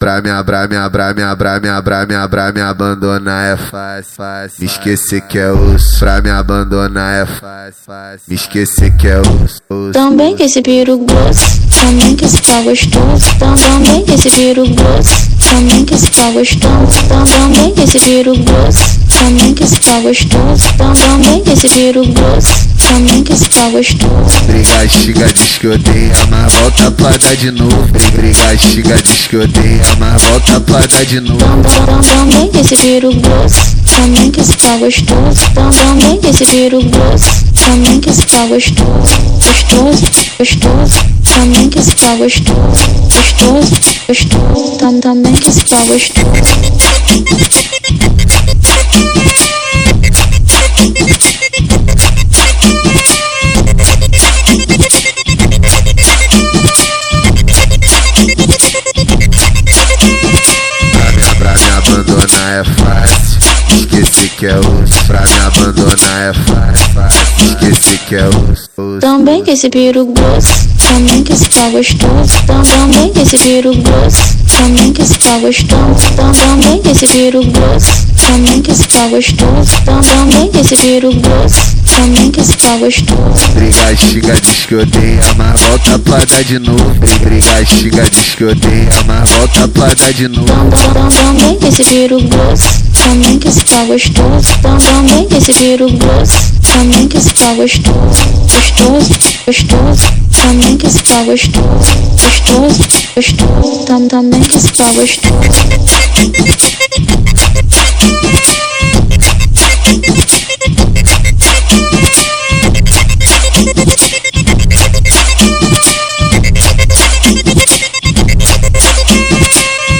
2025-02-13 18:01:30 Gênero: Funk Views